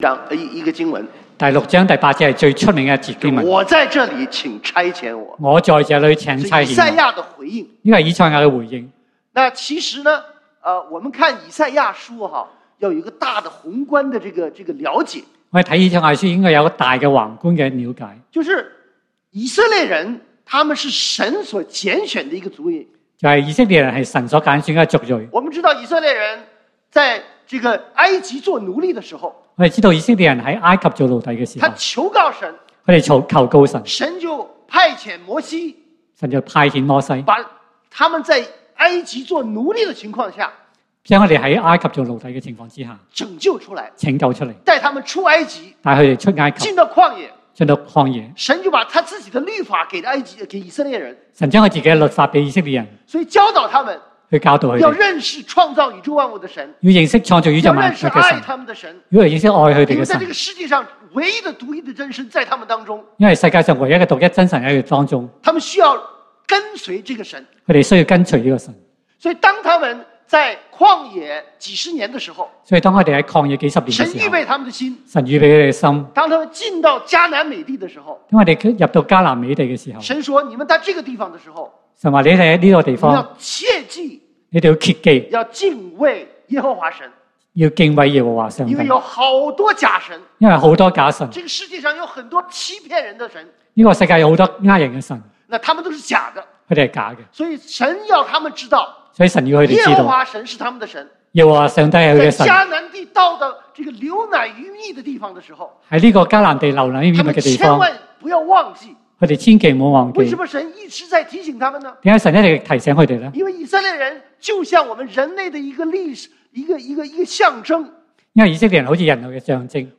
宣教年會：主人的差遣